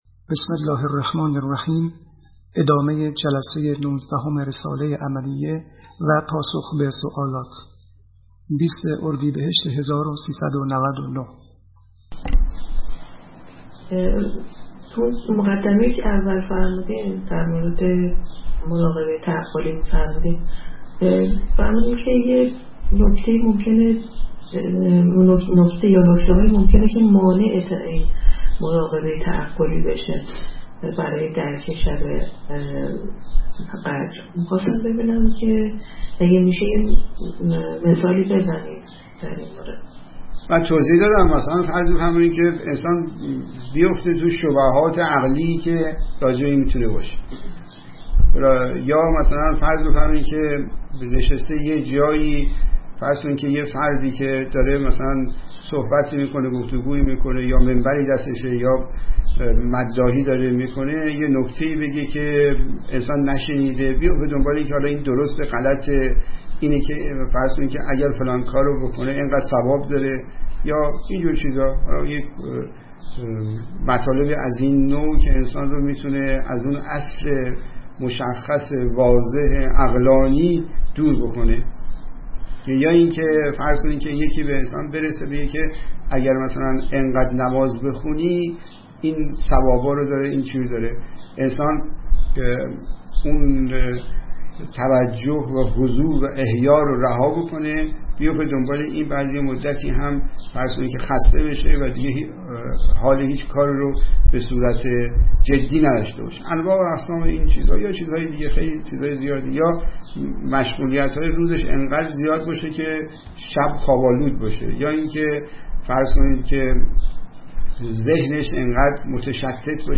متن : شب قدر (پرسش‌وپاسخ)